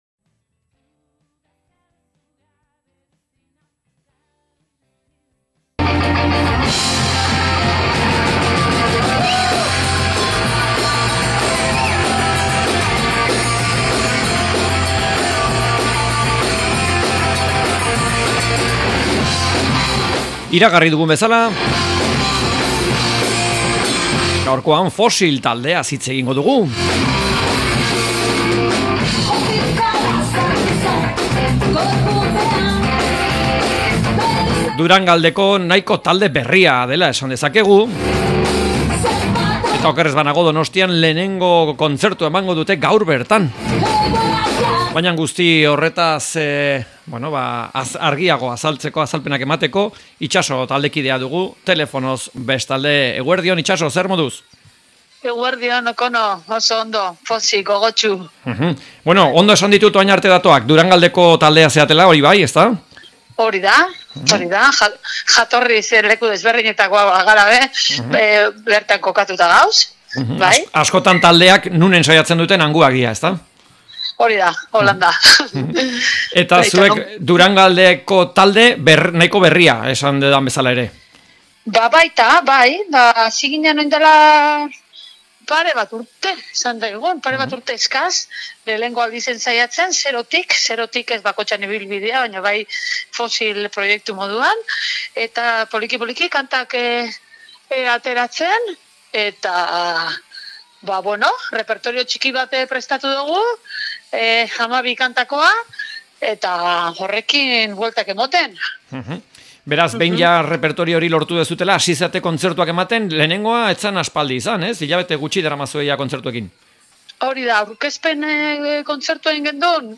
Fosil taldeari elkarrizketa